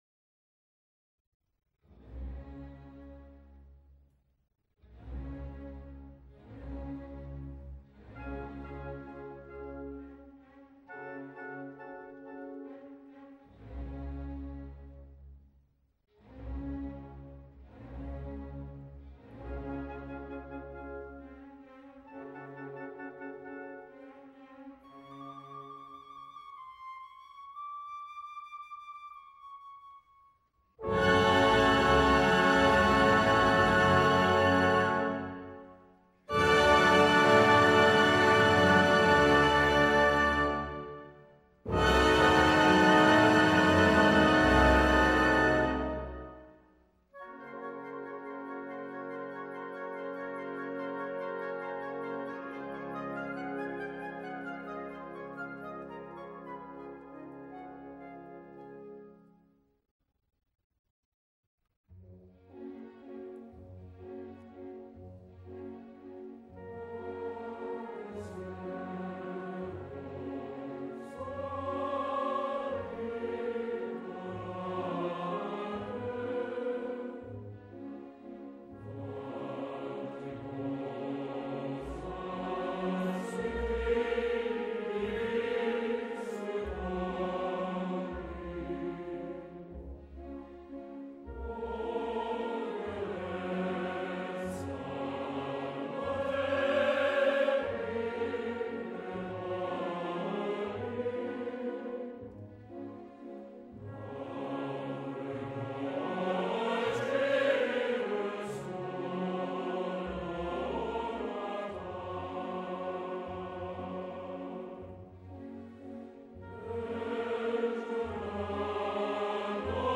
Verdi - Va, pensiero Gefangenenchor - Nabucco.mp3